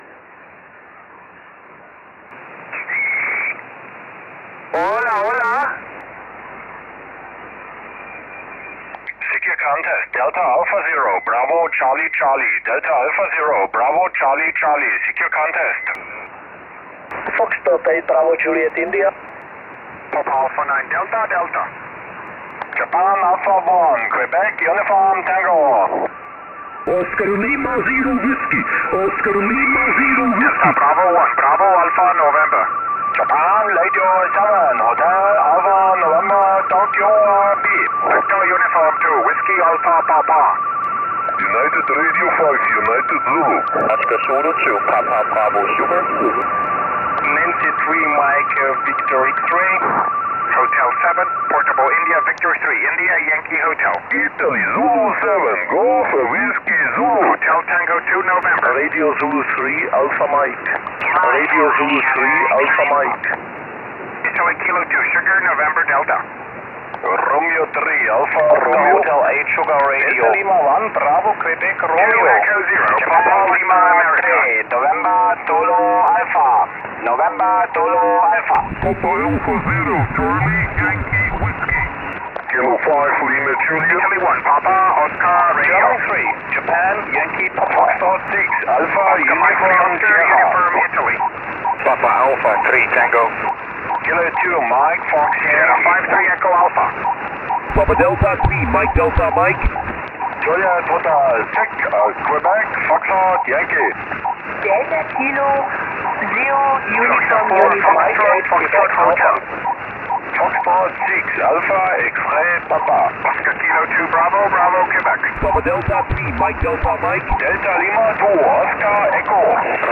Die Aufgabe war erneut, aus einem SSB-Pileup so viele Rufzeichen wie möglich korrekt zu loggen.